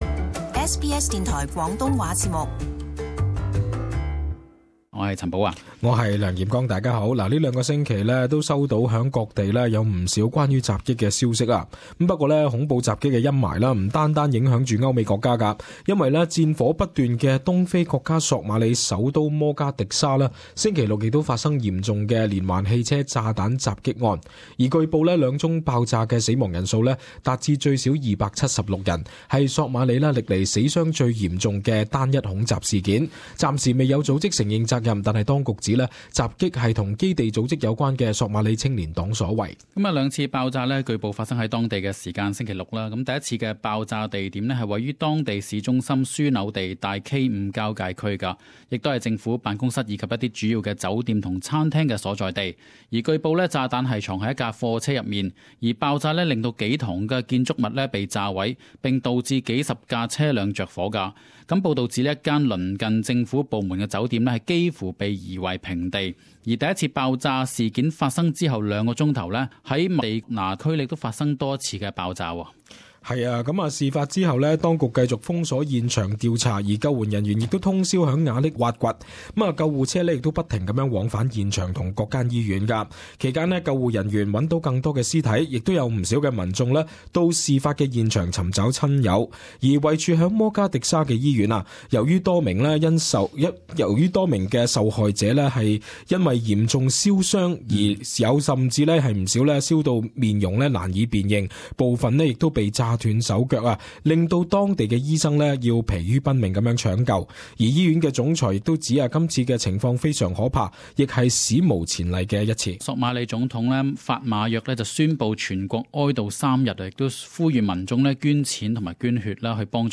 【時事報導】索馬里發生當地史上最嚴重連環恐襲